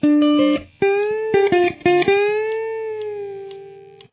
Guitar 2 :
Here is what the part above sounds like by itself :